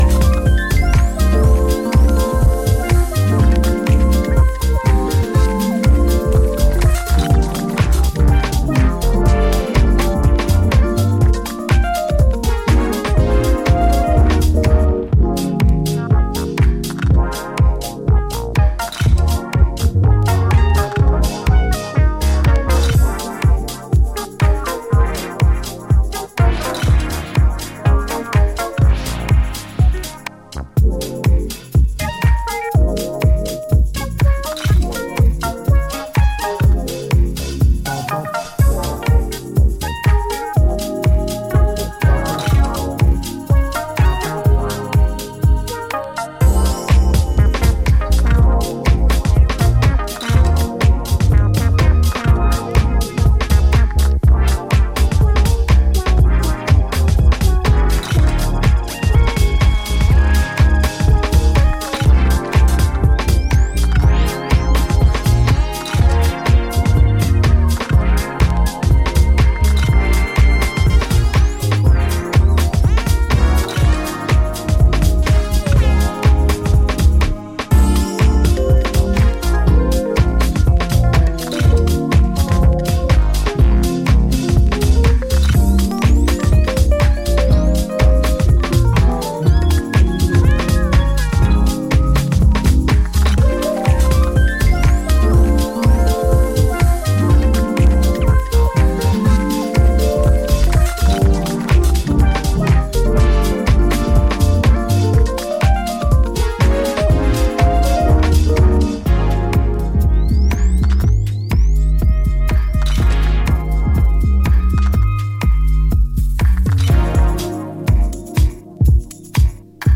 B面はテンポ良いメロデッシュなディープ・ハウス。
ジャンル(スタイル) HOUSE / DEEP HOUSE